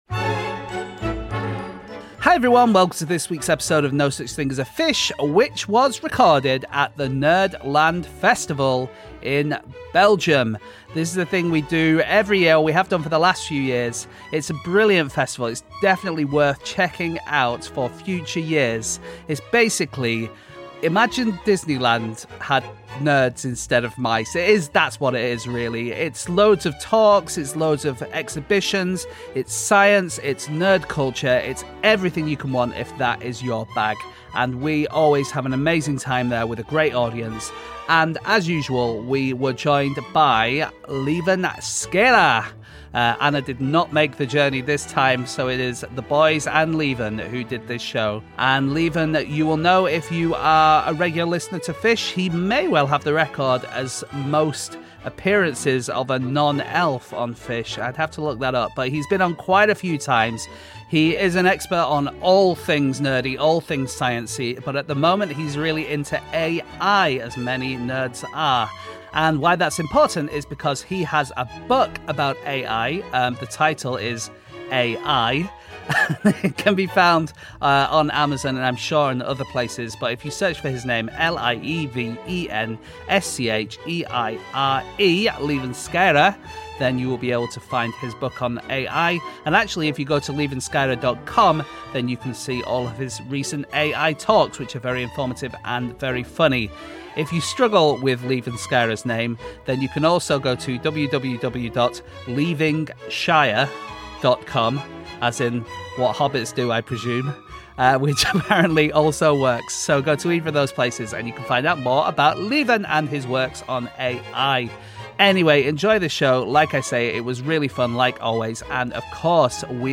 Live from the Nerdland Festival